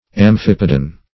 Meaning of amphipodan. amphipodan synonyms, pronunciation, spelling and more from Free Dictionary.
amphipodan.mp3